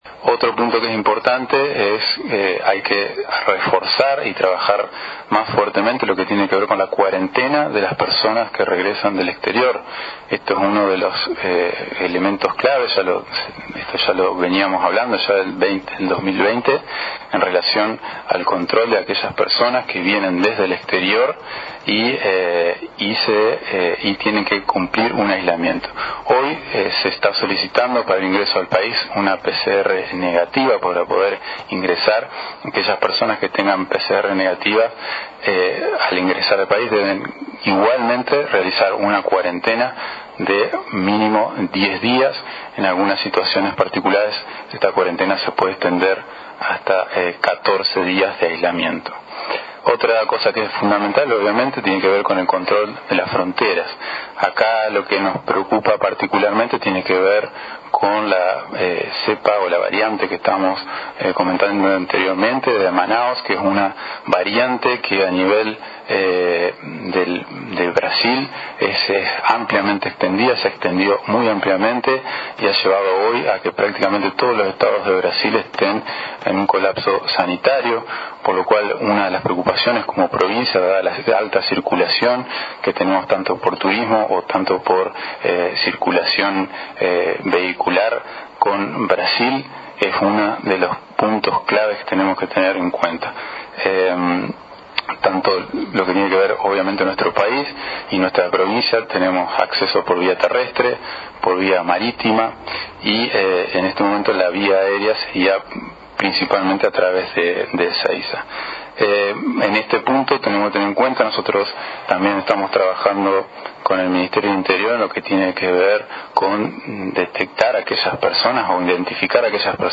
A través de una conferencia de prensa virtual, el Director provincial de Epidemiología, Doctor Diego Garcilazo, habló sobre dos temas que atraviesan, el operativo con el coronavirus, que lleva adelante esta gestión provincial.